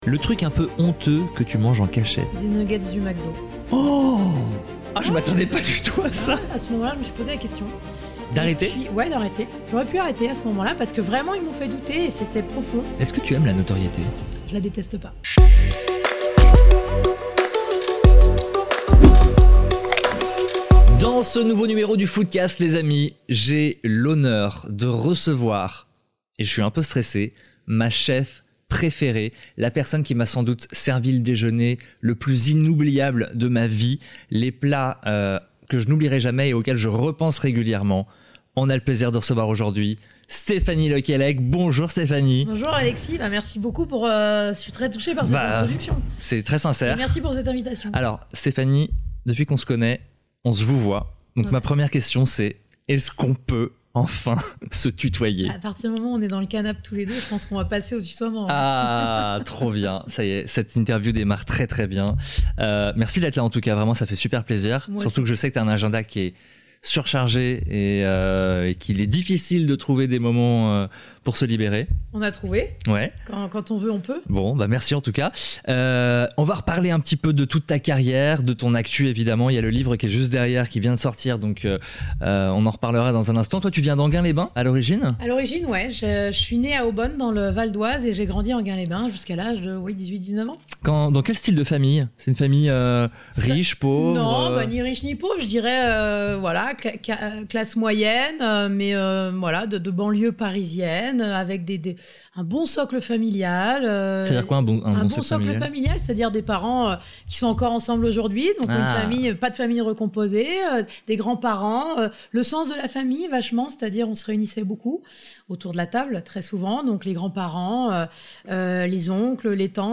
FoodCast – J’ai enfin interviewé Stéphanie Le Quellec !